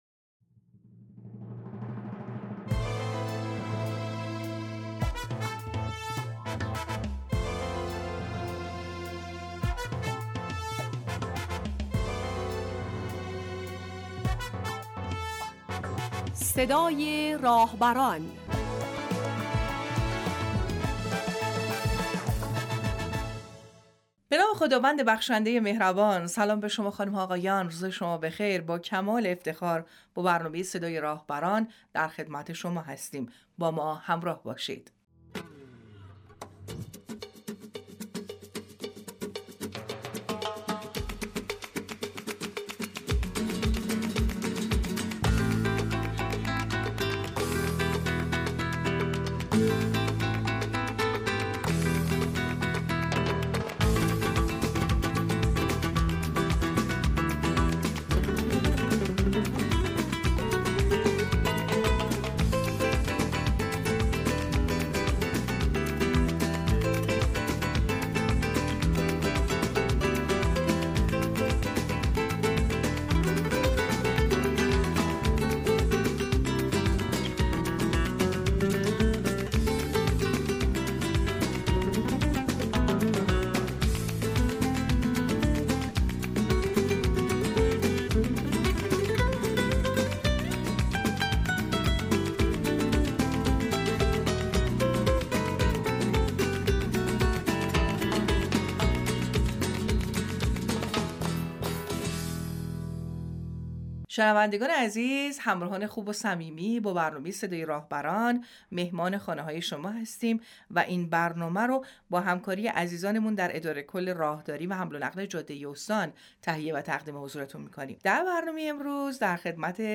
بشنوید| صدای راهبران مصاحبه با رییس مرکز مدیریت راه‌های چهارمحال‌وبختیاری